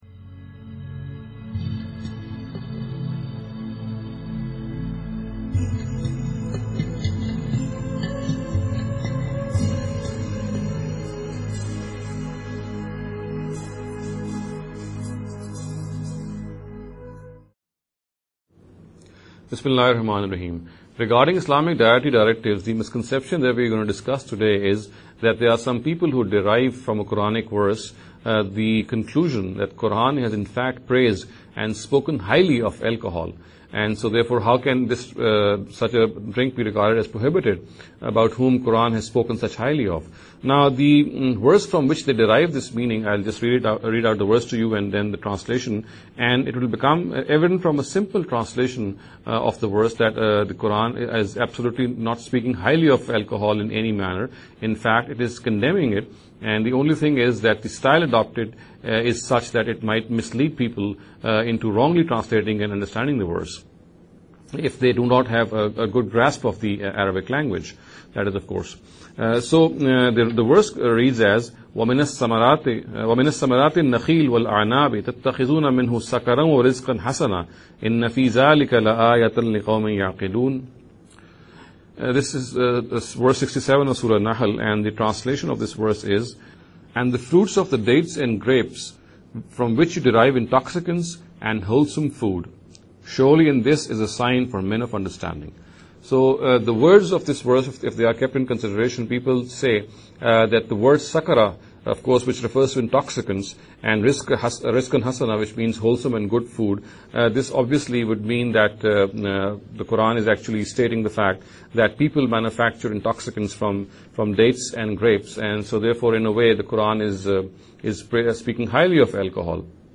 This lecture series will deal with some misconception regarding The Dietary Directives of Islam.